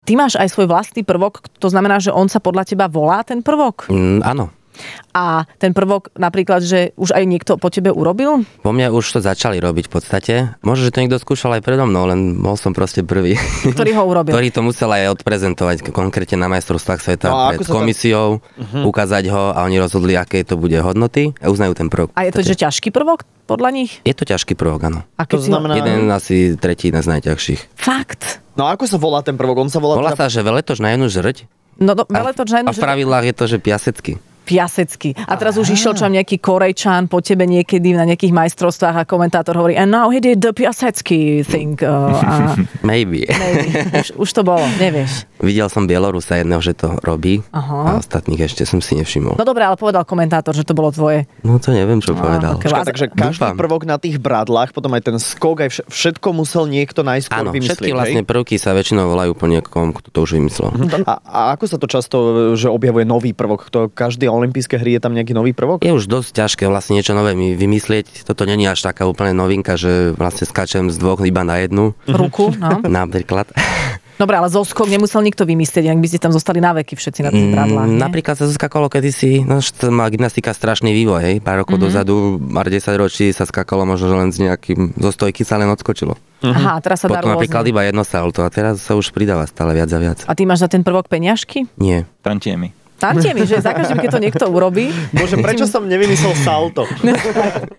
Do Rannej šou prišiel slovenský gymnasta, podľa ktorého už pomenovali aj konkrétny prvok!